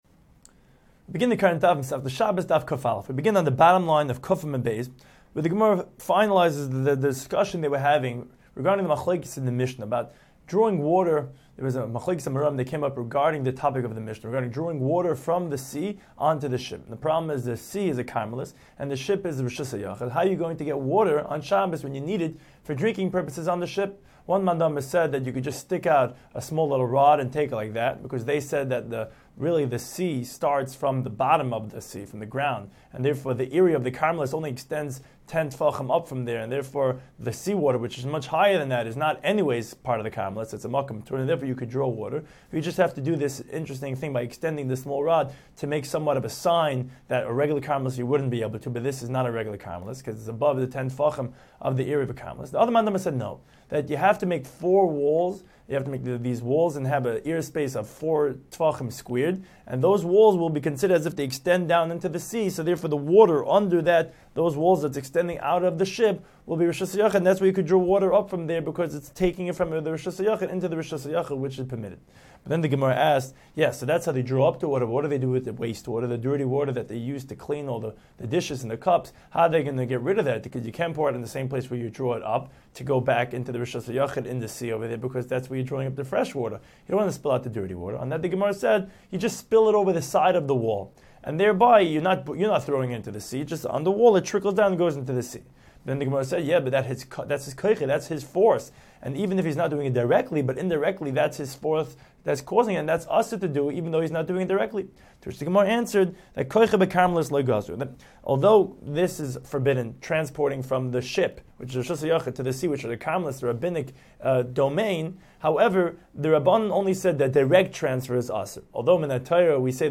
Daf Hachaim Shiur for Shabbos 101